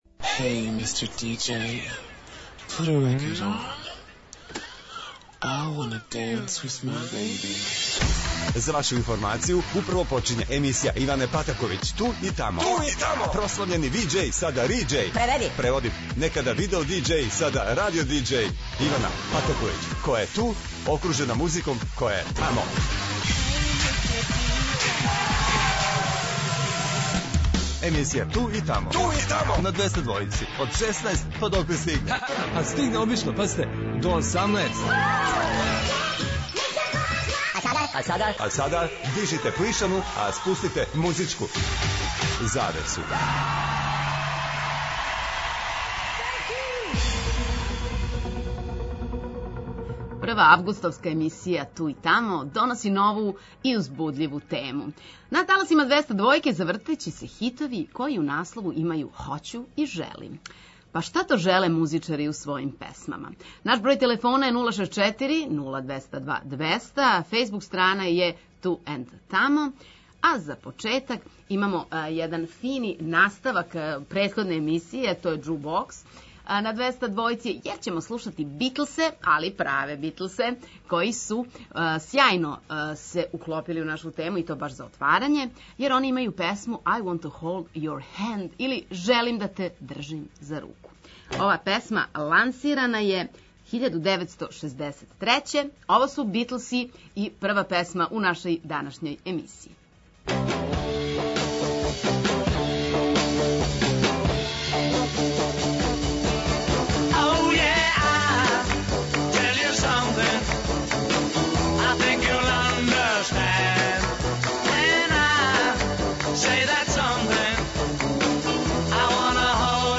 На таласима Двестадвојке завртеће се хитови који у наслову имају хоћу и желим. Па шта то желе музичари у својим песмама?
Очекују вас велики хитови, страни и домаћи, стари и нови, супер сарадње, песме из филмова, дуети и још много тога.